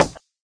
woodplastic2.ogg